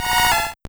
Cri de Phanpy dans Pokémon Or et Argent.